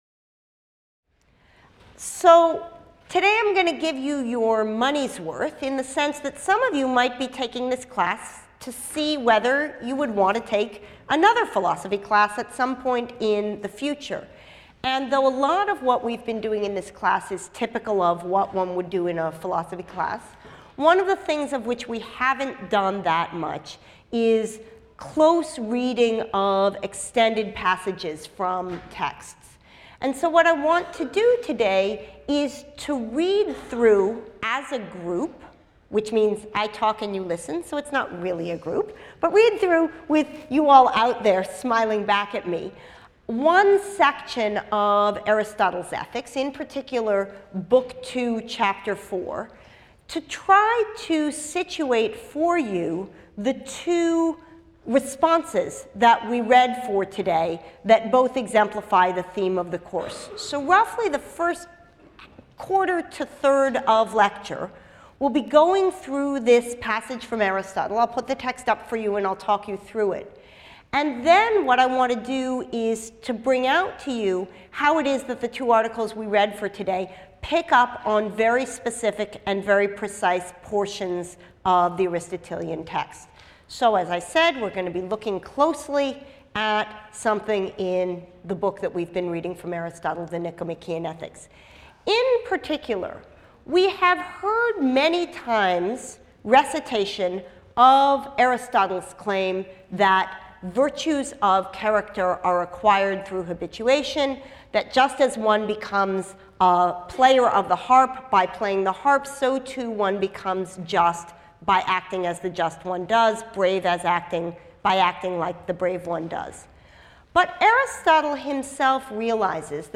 PHIL 181 - Lecture 10 - Virtue and Habit II | Open Yale Courses